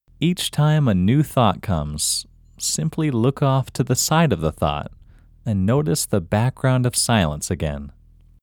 Locate IN English Male 18